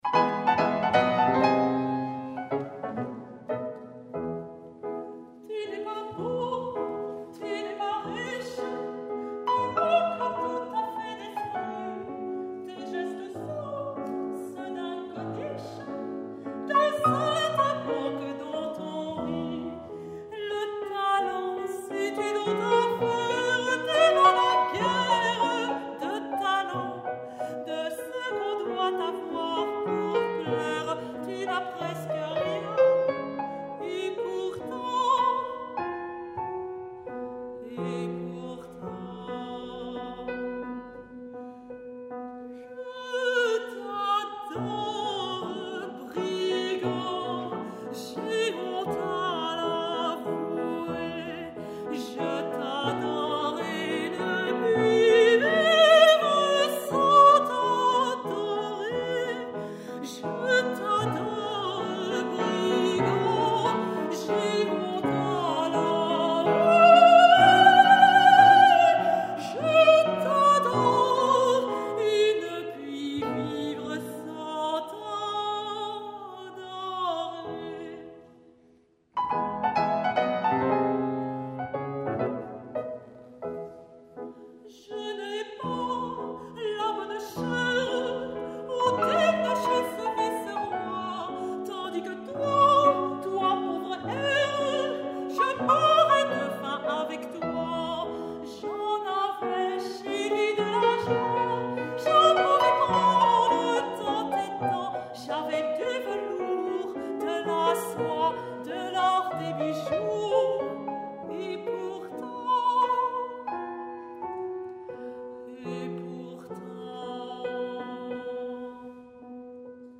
Artiste Lyrique